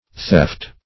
Theft \Theft\ (th[e^]ft), n. [OE. thefte, AS.
theft.mp3